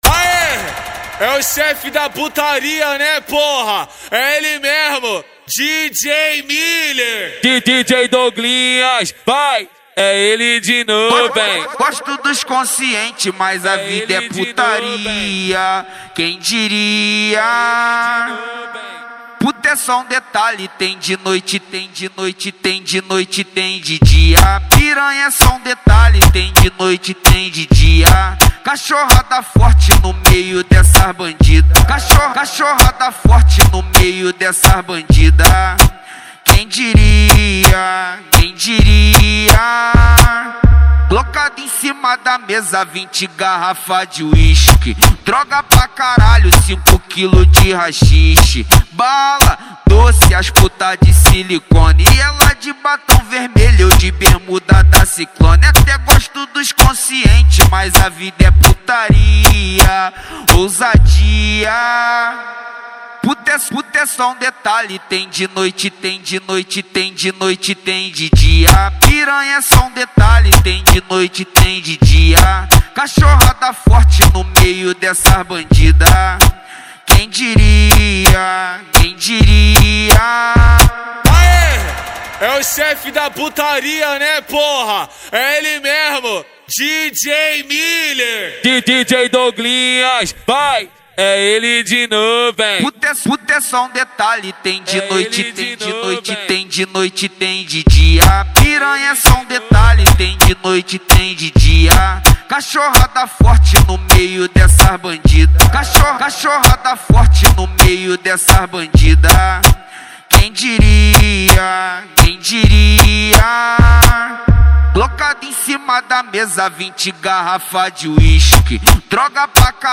Gênero: Funk